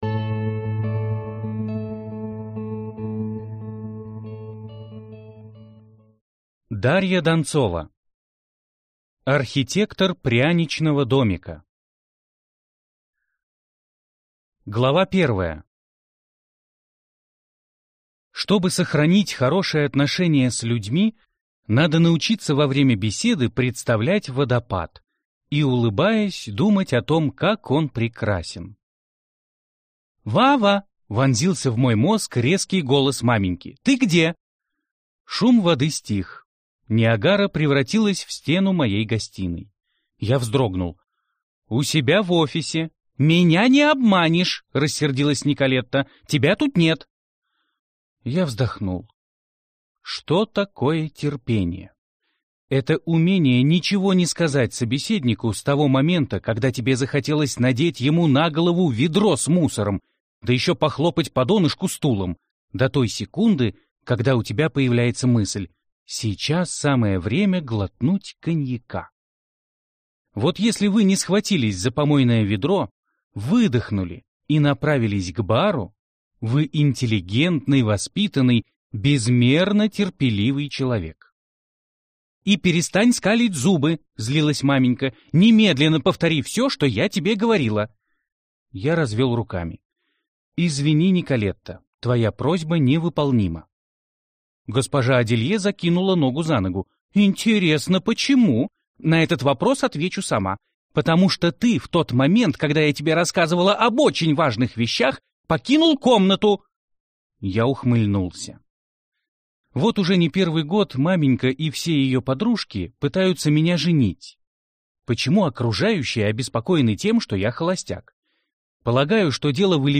Аудиокнига Архитектор пряничного домика - купить, скачать и слушать онлайн | КнигоПоиск